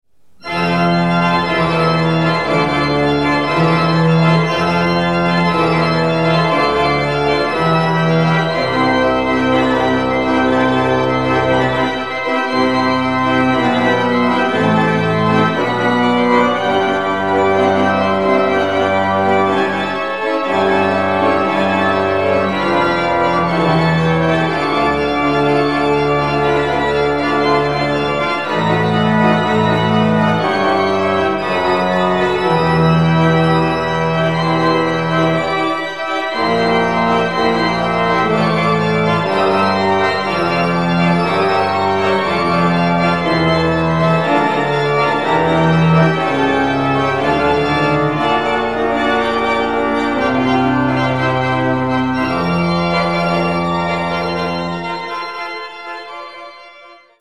the virtual pipe organ
So what does it sound like when everything is put together?